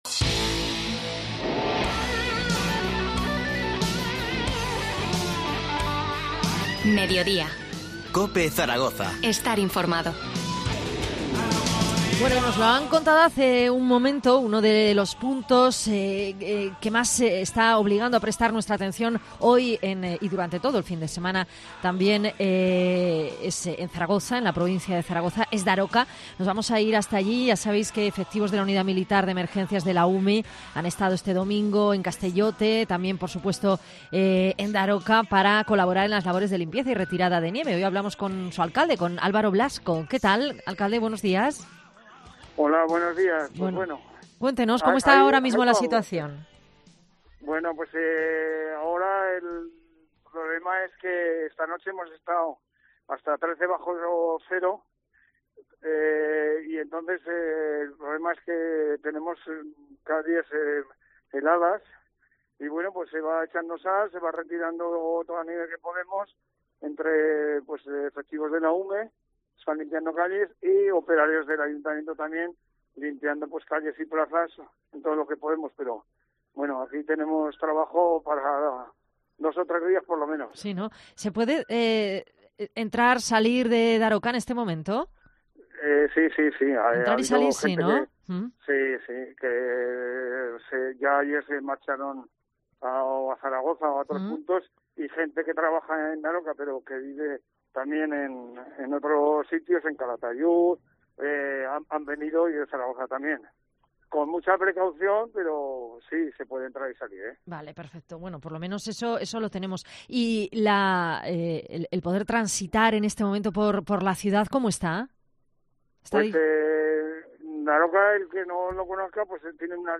Entrevista a Álvaro Blasco, alcalde de Daroca